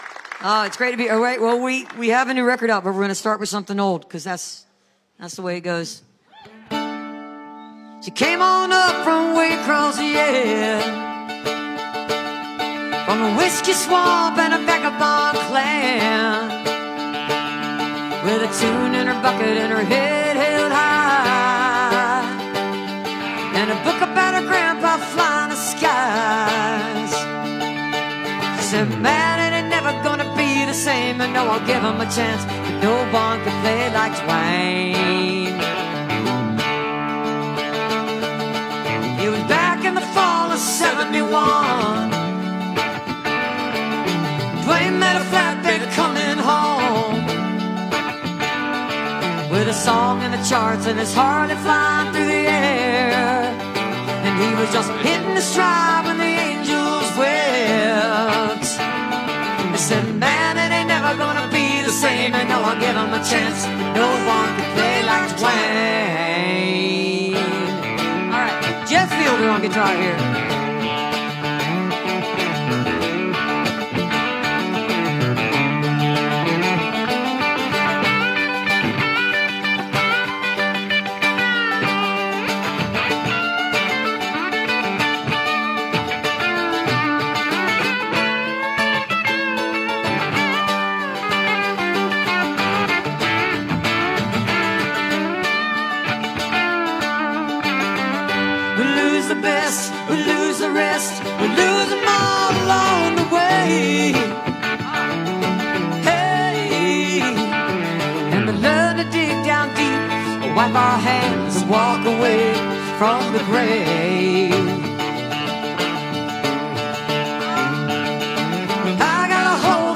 (audio capture from a facebook live stream)